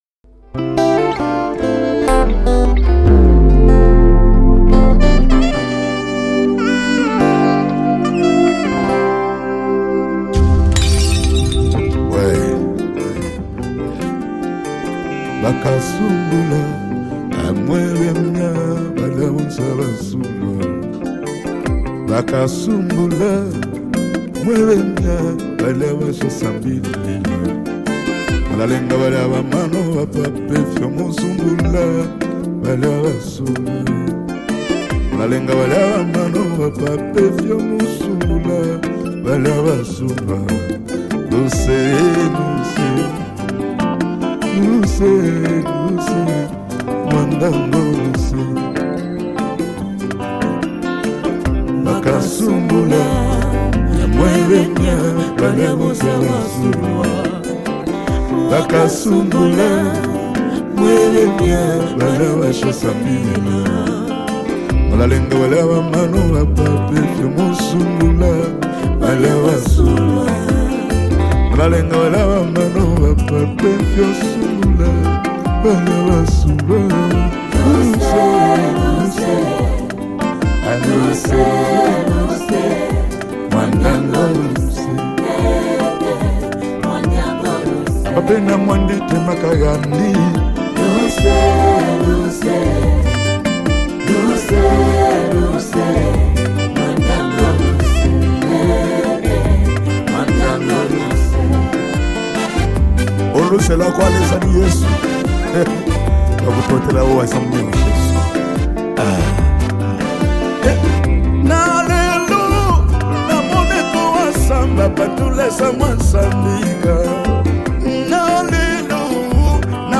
heartfelt vocals filled with vulnerability